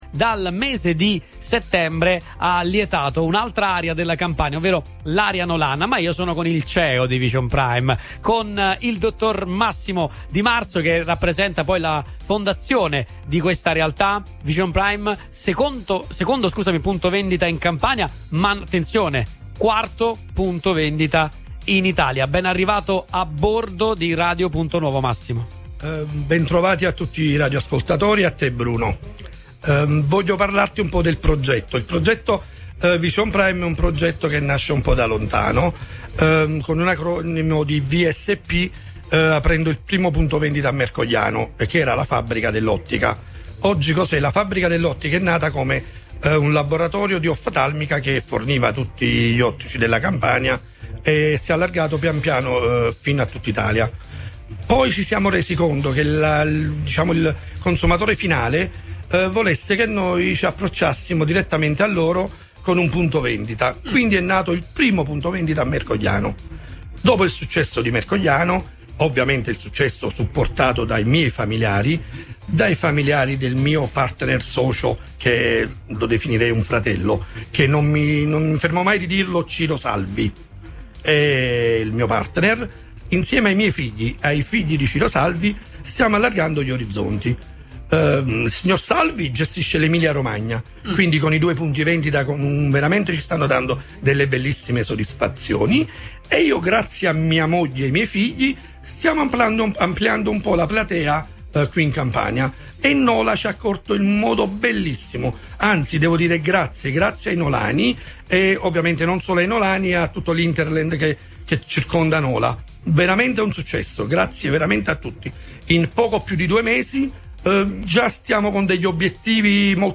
La regia mobile di Radio Punto Nuovo ha reso l’evento ancora più speciale, trasmettendo interviste in diretta con i protagonisti di Vision Prime e condividendo con gli ascoltatori lo spirito gioioso della giornata.